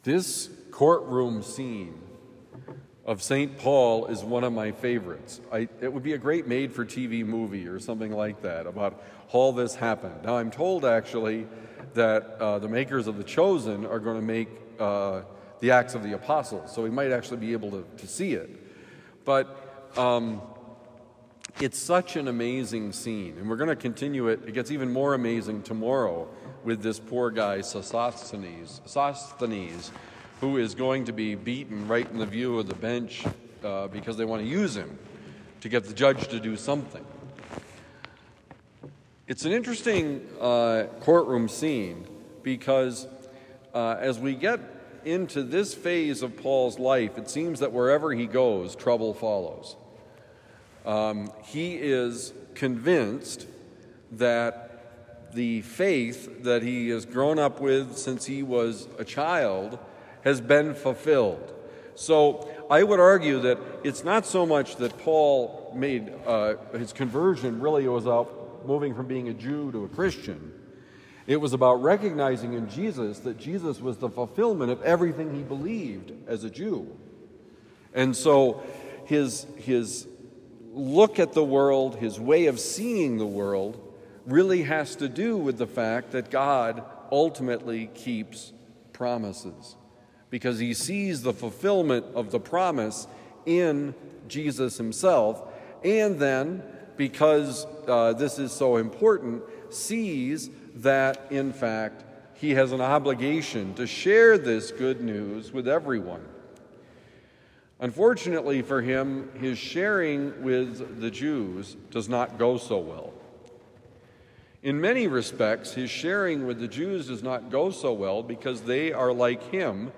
That they may be one: Homily for Thursday, June 5, 2025